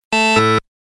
Звуки неправильного ответа
На этой странице собрана коллекция звуковых эффектов, обозначающих неправильный ответ или неудачу.